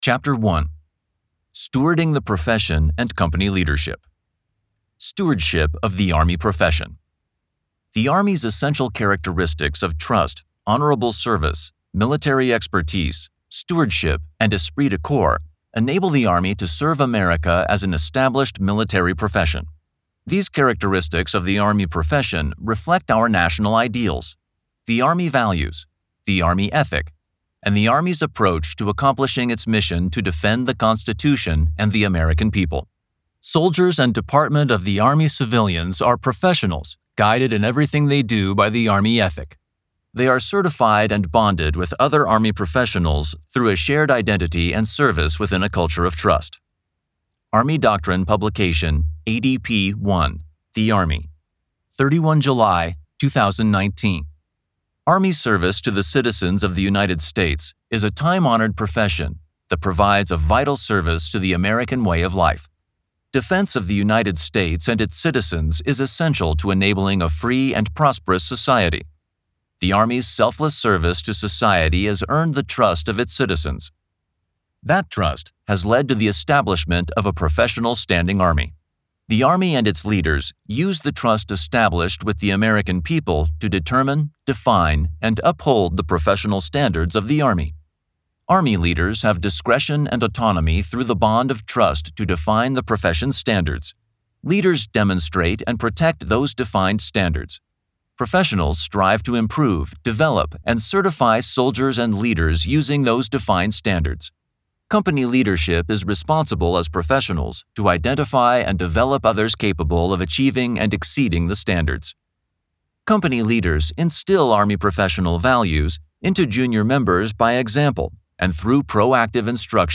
This is the download page for the Center for Army Lessons Learned Audiobook of publication 22-04, The First 100 Days of Company Leadership (April 2022).